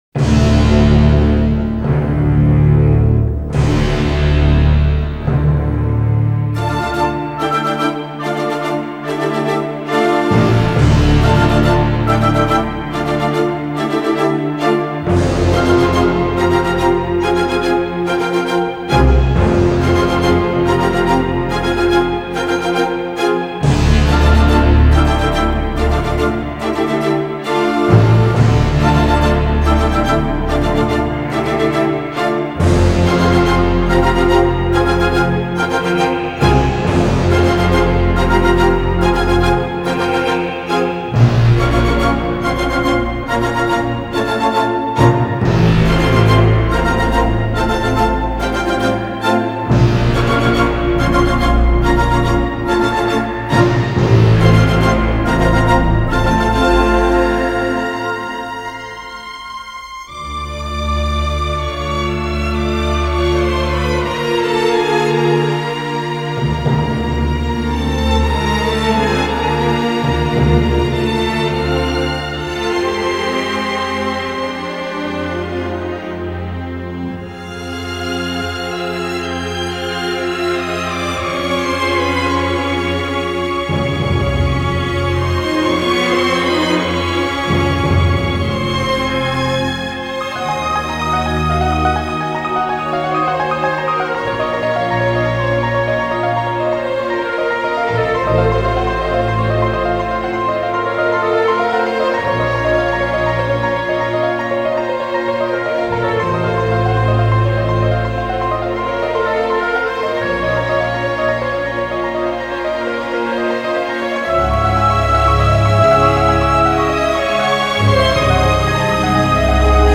장르: Electronic, Jazz, Pop
스타일: Modern Classical, Easy Listening